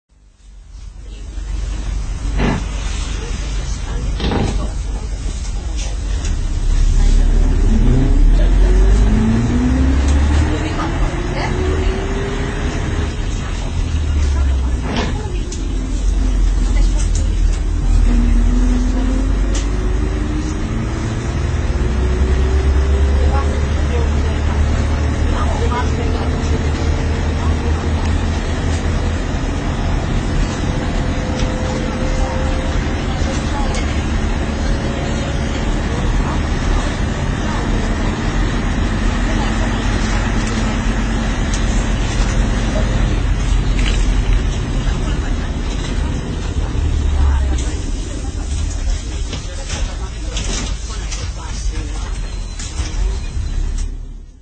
富士５Ｅボディを架装したＣＪＭ最後の走行音です。
上で紹介している３Ｅボディ車より低音が強くなっているのが特徴です。
ＰＡＲＴ２　綾４１系統（当時は系統番号なし） 中央１丁目〜国分 （２２７ＫＢ）